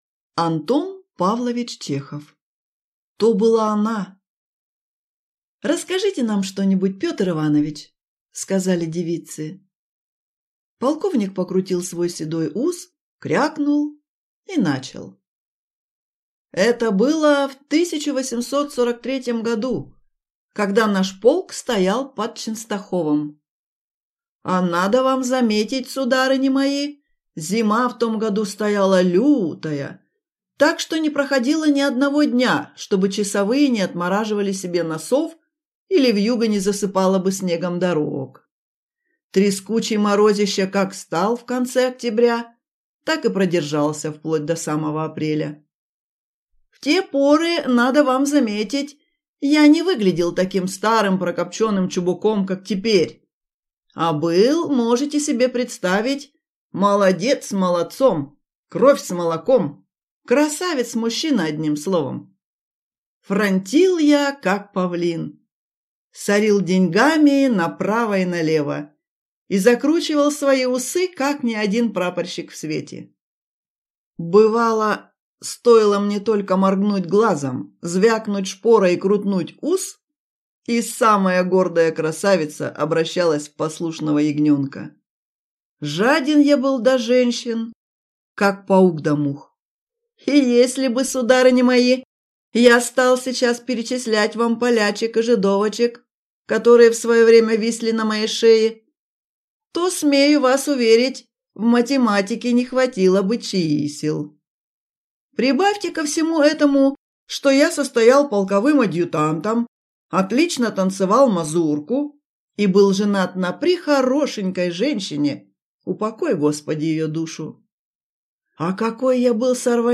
Аудиокнига То была она!